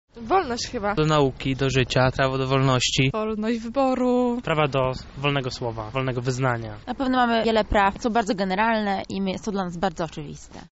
O znajomość Praw Człwieka zapytaliśmy mieszkańców Lublina.